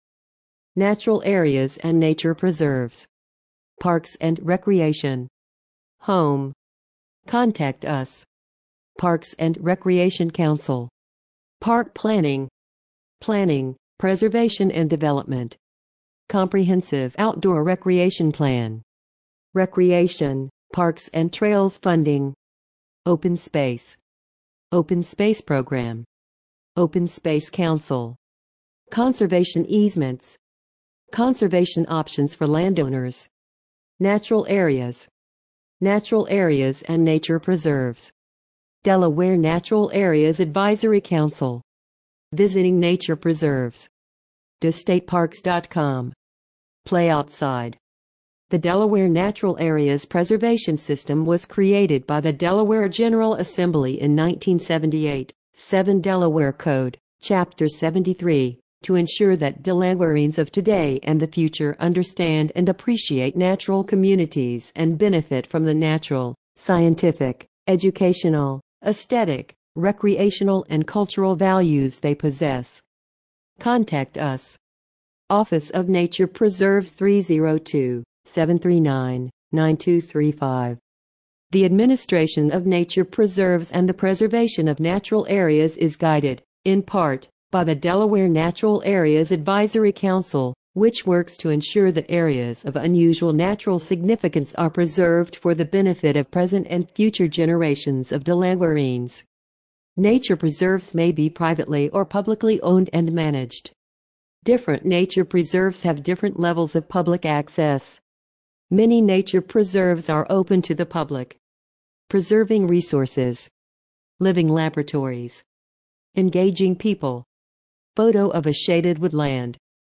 Listen to this page using ReadSpeaker